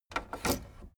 Gemafreie Sounds: Kassettenspieler